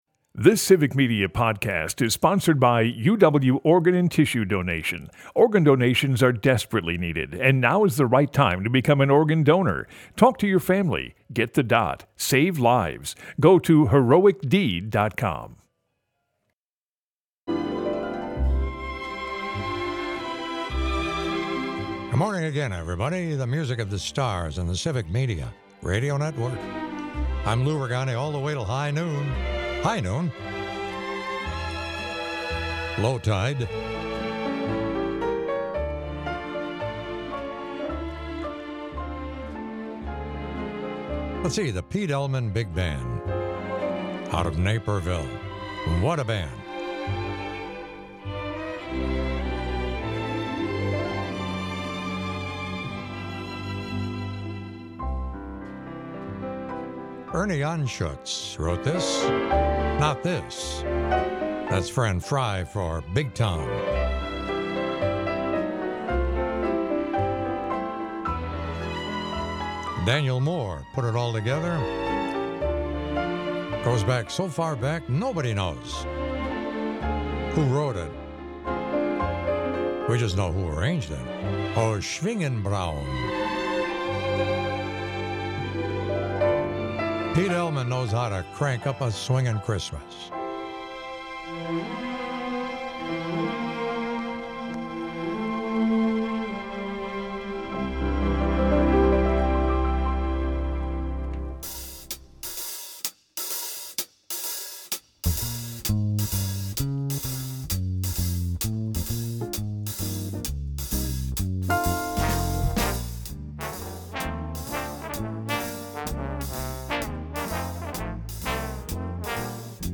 Broadcasts live 7 a.m. to noon Sunday mornings across Wisconsin.